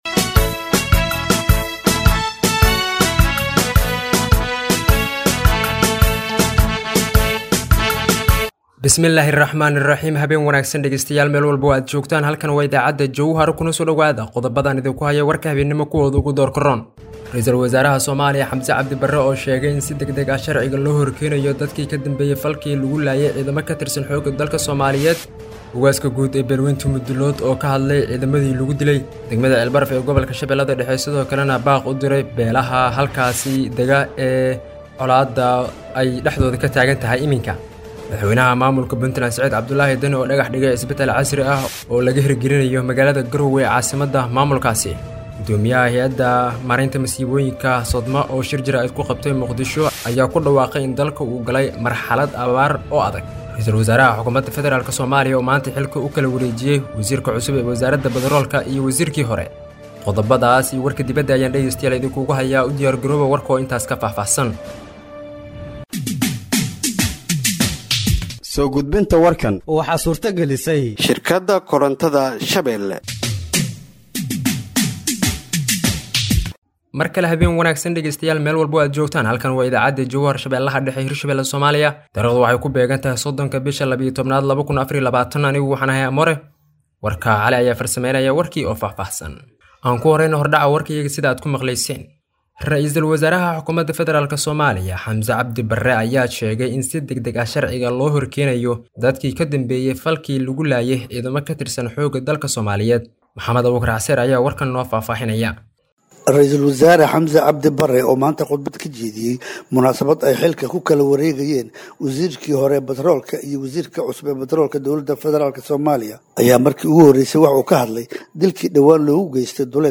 Dhageeyso Warka Habeenimo ee Radiojowhar 30/12/2024
Halkaan Hoose ka Dhageeyso Warka Habeenimo ee Radiojowhar